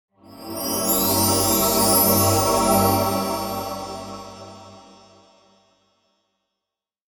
Mystical Shiny Sparkling Transition Sound Effect
Description: Mystical shiny sparkling transition sound effect.
Enhance intros, logos, and scene changes with this enchanting and uplifting sound.
Genres: Sound Logo
Mystical-shiny-sparkling-transition-sound-effect.mp3